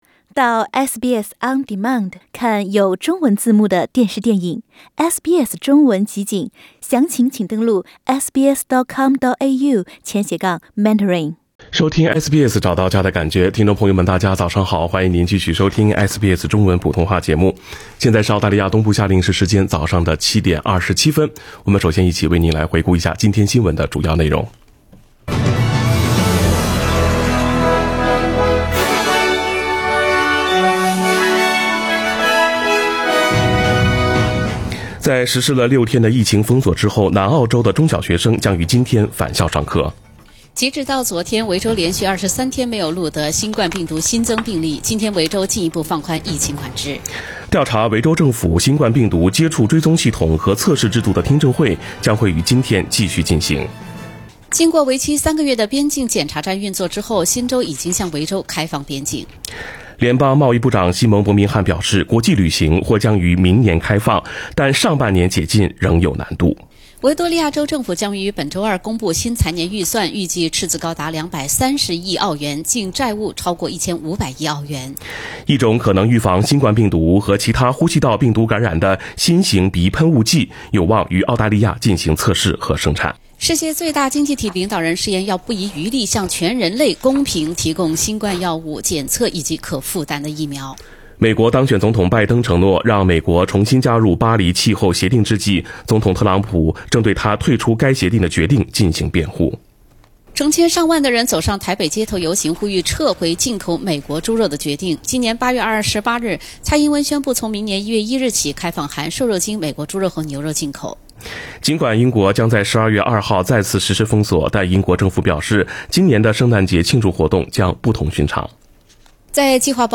SBS早新聞（11月23日）